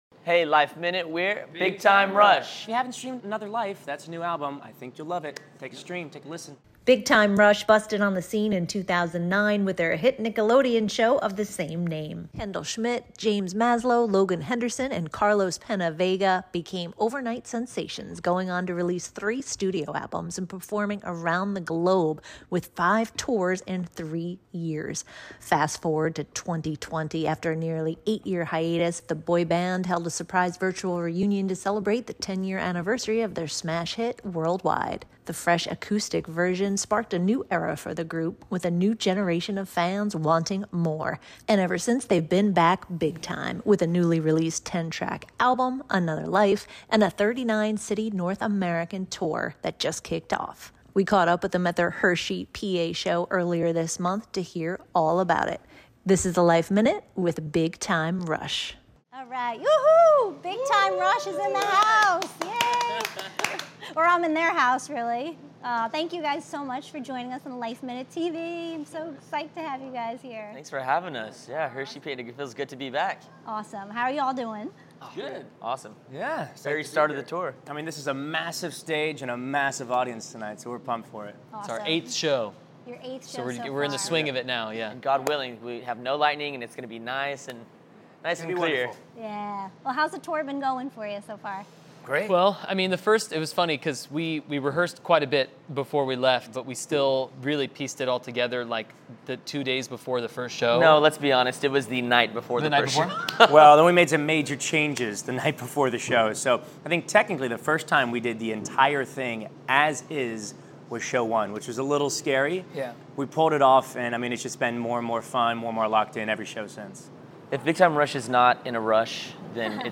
With little downtime, the boys are back with a newly released 10-track album, Another Life, and a 39-city North American Can’t Get Enough Tour that kicked off last month. We caught up with the band at their stop in Hershey, PA, to hear about the exciting things happening for these guys who just want to spread positivity through their music.